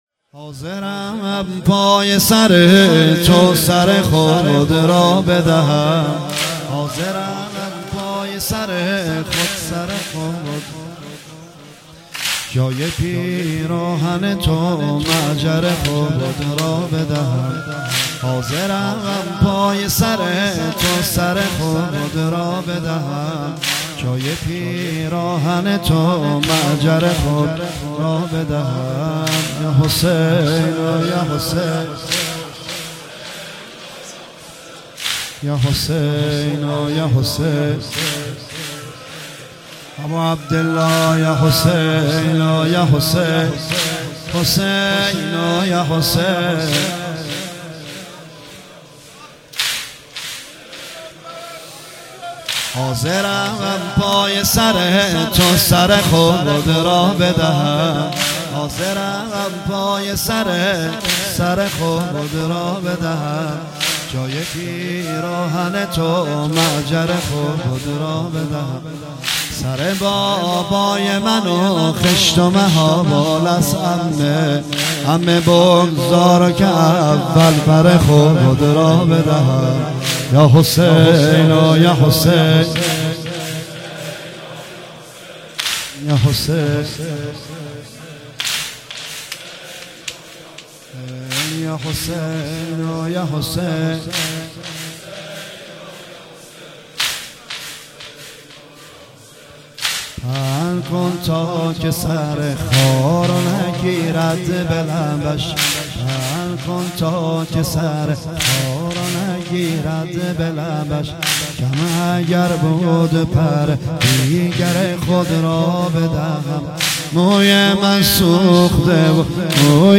مناسبت : شب سوم محرم
مداح : نریمان پناهی قالب : واحد